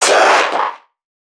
NPC_Creatures_Vocalisations_Infected [94].wav